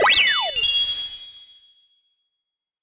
Sound effect of Goal Pole Catch Top in Super Mario 3D World.
SM3DW_Goal_Pole_Catch_Top.oga